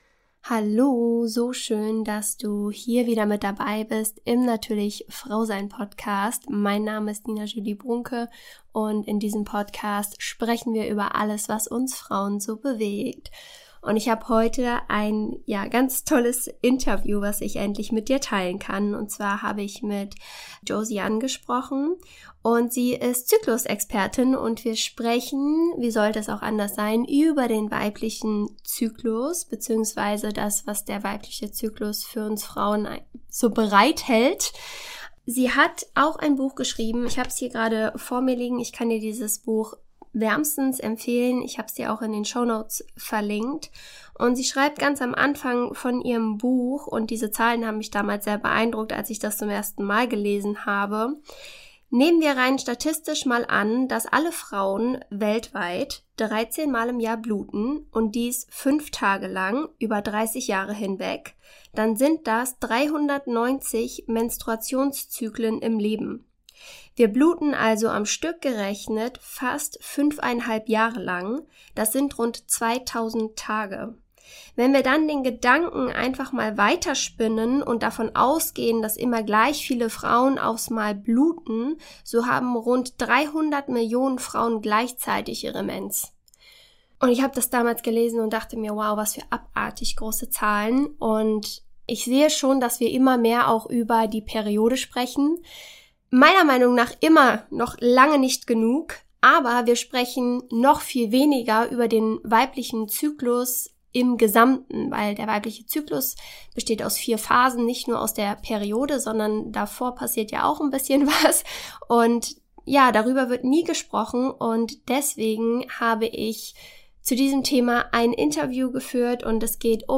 Wir tauchen in einem ehrlichen Gespräch zwischen Frauen in die faszinierende Welt des weiblichen Zyklus ein.